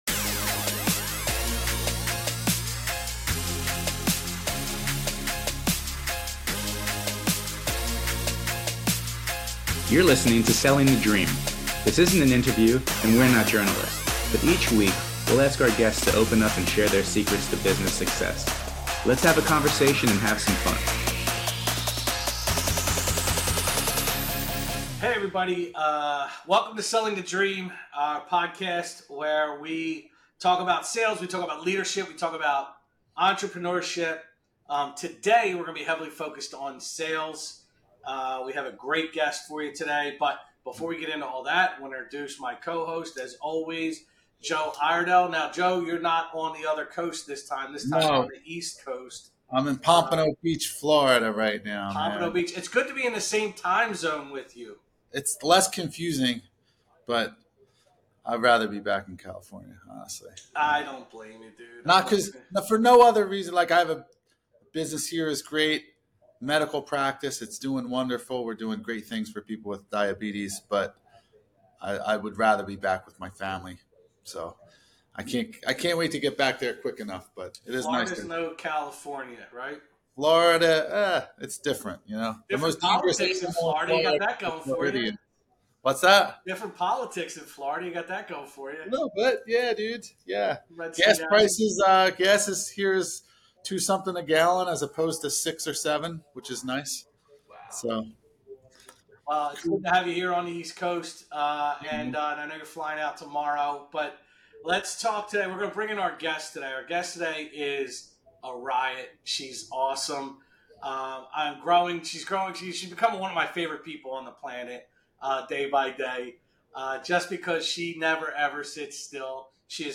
This is a candid, strategy-packed conversation for anyone in the trenches of real estate or mortgage.